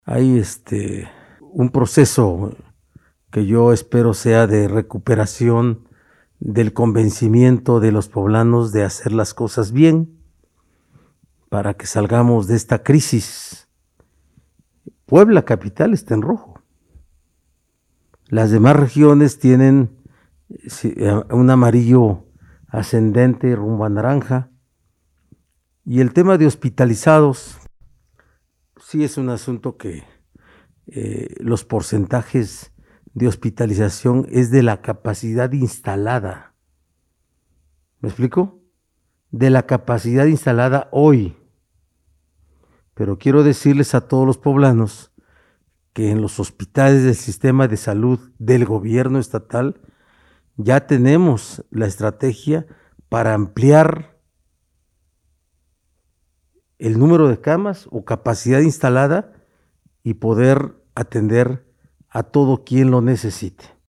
En la habitual videoconferencia de prensa que ofrece en Casa Aguayo, el mandatario estatal llamó a la sociedad a recuperar la concientización para acatar nuevamente las medidas sanitarias, a fin de poder salir adelante de esta crisis.